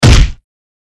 hit.mp3